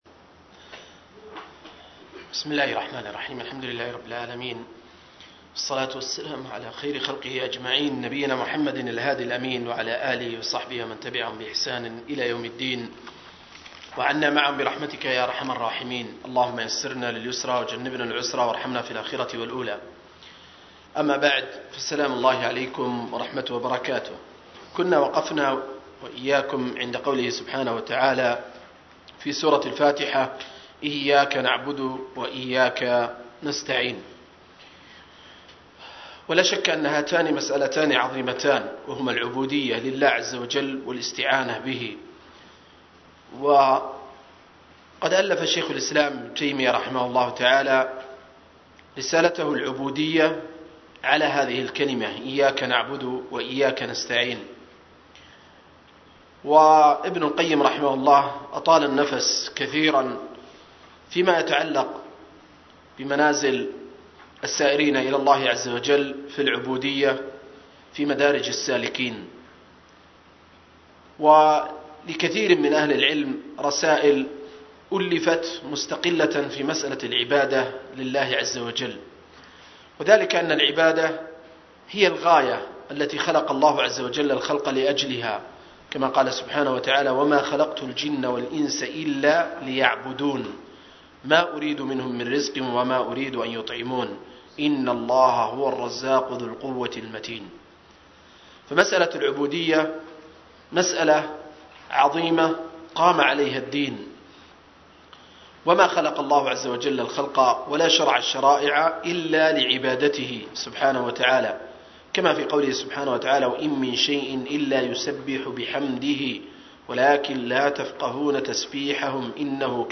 06-التفسير الموضوعي الميسر لقصار المفصل – الدرس السادس